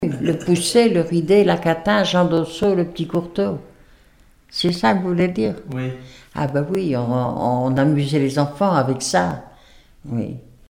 formulette enfantine : jeu des doigts
Témoignages et musiques
Pièce musicale inédite